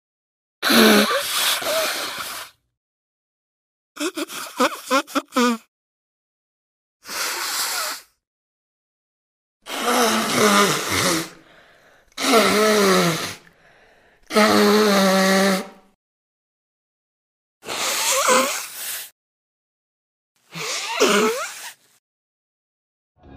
Tổng hợp tiếng Xì mũi nước khi bị cảm lạnh
Tổng hợp Tiếng Cười Hay Tiếng Xì mũi, Hỉ mũi nước (giọng phụ nữ)
Thể loại: Tiếng hoạt động con người
Description: Âm thanh chân thực, rõ nét mô tả hành động xì mũi do nghẹt mũi, sổ mũi, chảy nước mũi khi bị cảm cúm.
Hiệu ứng mô tả chi tiết tiếng xì mạnh từ mũi ra khăn giấy, tạo cảm giác chân thật và sống động, dùng cho chỉnh sửa video, dựng phim, hậu kỳ, clip...
tong-hop-tieng-xi-mui-nuoc-khi-bi-cam-lanh-www_tiengdong_com.mp3